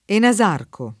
ENASARCO [ ena @# rko ]